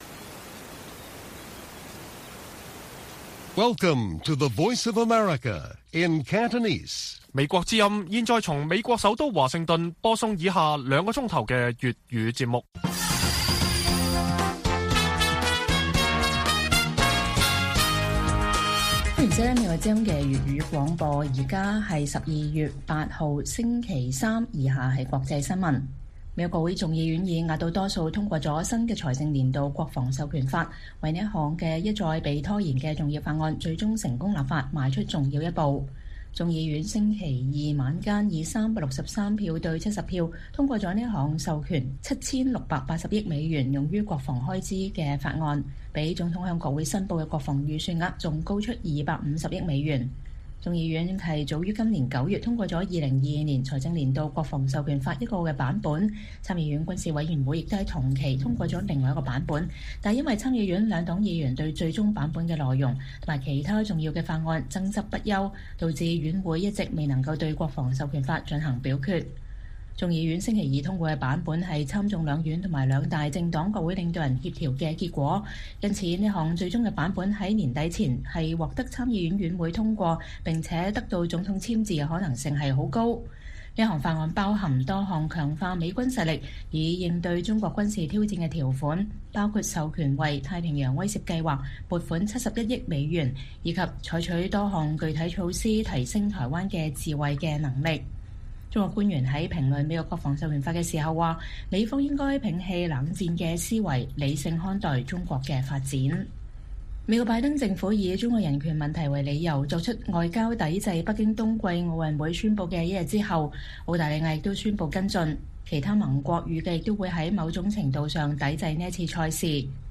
粵語新聞 晚上9-10點: 國會眾院通過新財年國防授權法協調版本